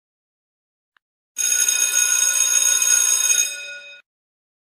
School Bell Eff Botão de Som